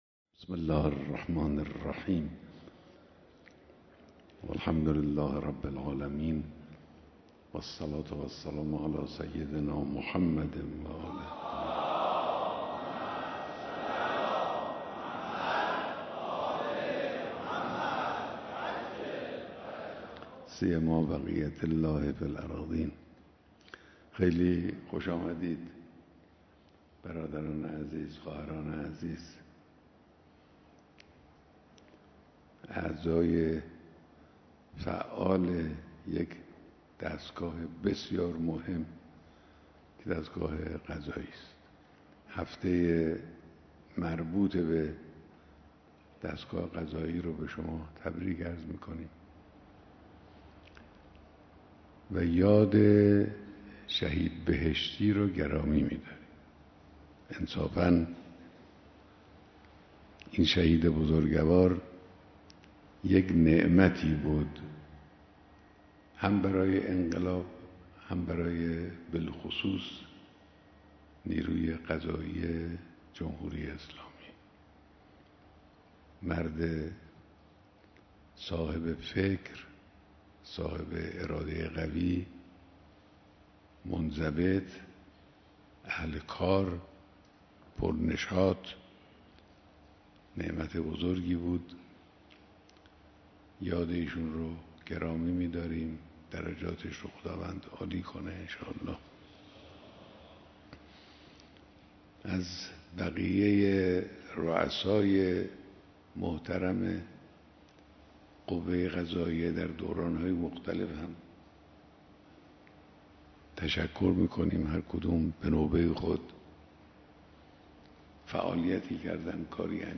بیانات در دیدار رئیس، مسئولان و کارکنان قوه قضائیه